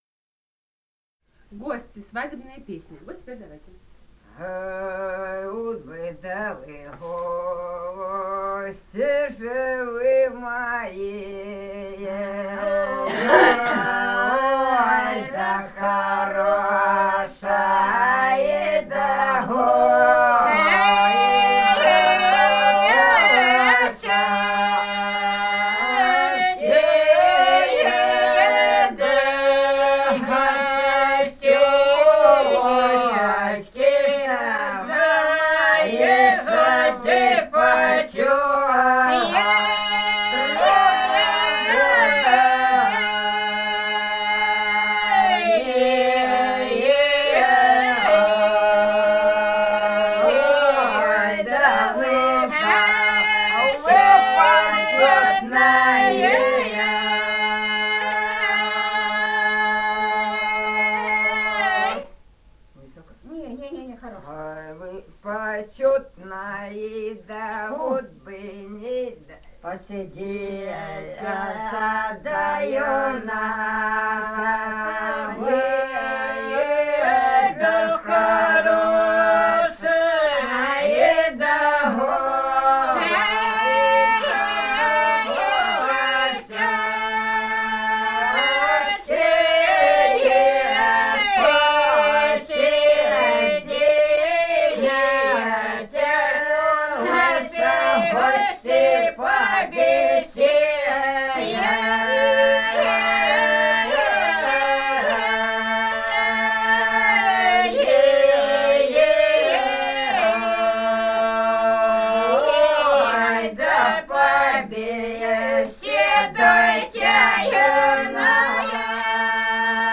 полевые материалы
«А уж бы да вы гости» (лирическая на свадьбе, «угощение сватов»).
Ростовская область, г. Белая Калитва, 1966 г. И0941-13